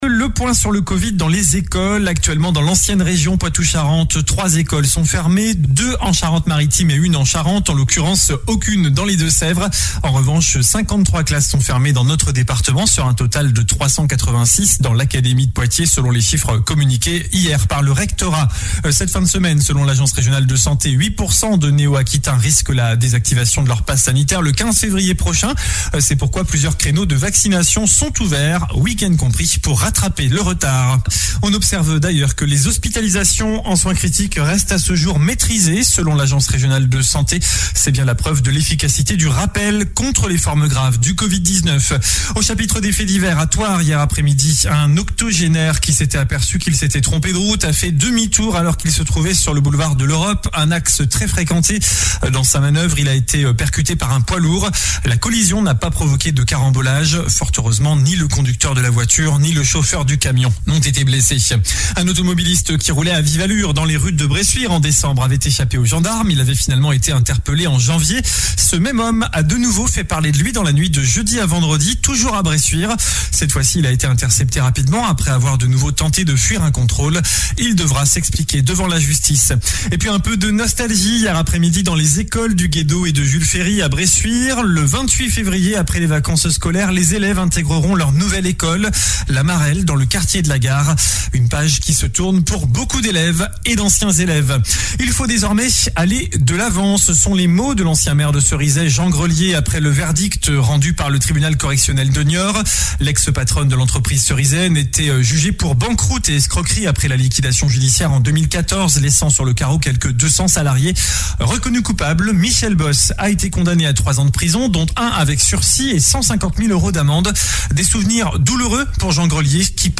Journal du samedi 12 février